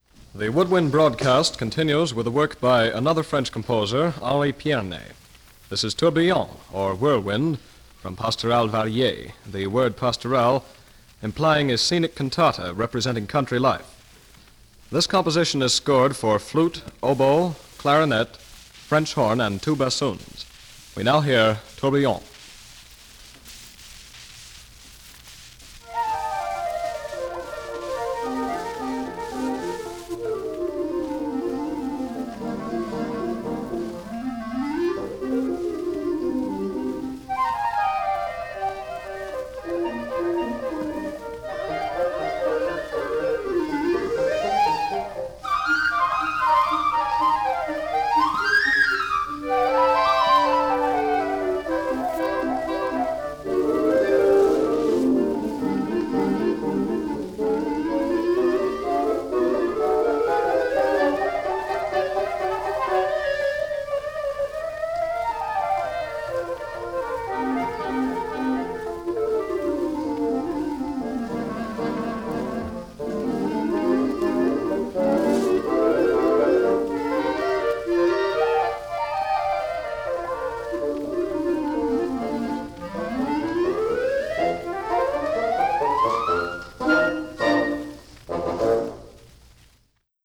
The following pieces were performed at the Curtis Institute of Music by various wind ensembles from 1936 to 1941 under the direction of Marcel Tabuteau.
Format: 78 RPM